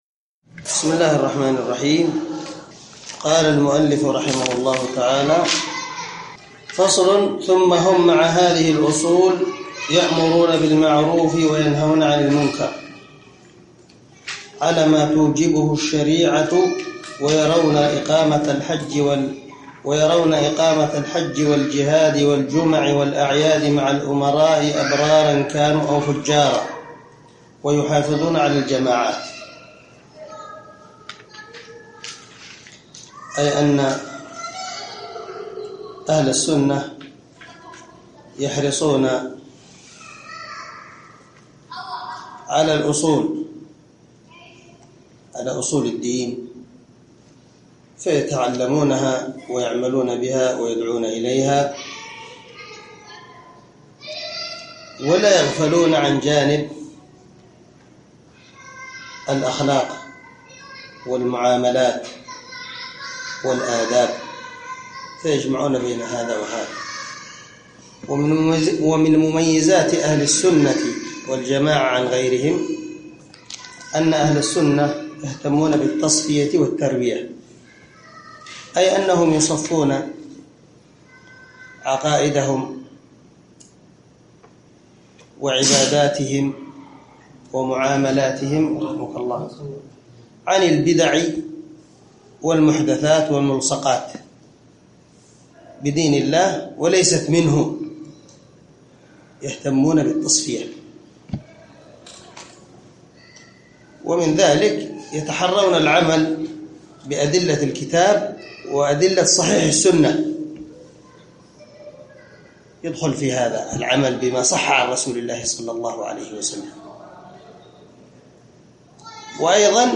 شرح العقيدة الواسطية للعلامة محمد بن خليل هراس رحمه الله - الدرس الثالث والثمانون
دار الحديث- المَحاوِلة- الصبيحة.